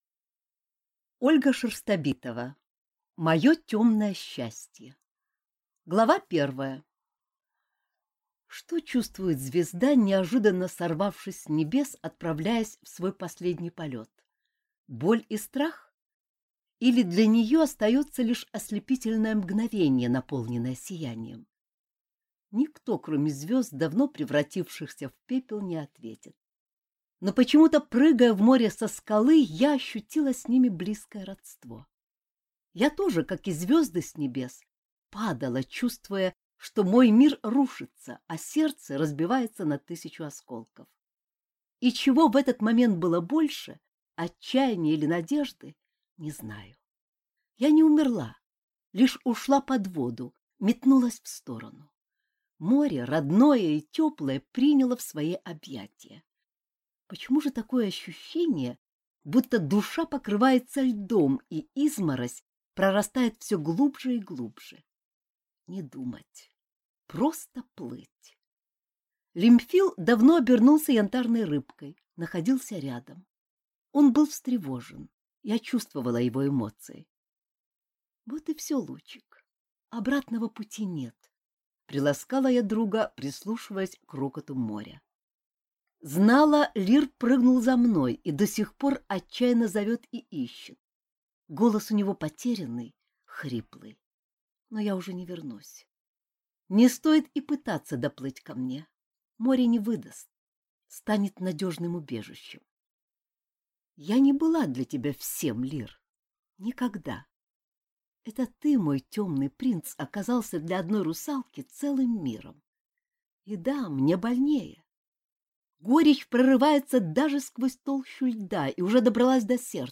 Аудиокнига Мое темное счастье | Библиотека аудиокниг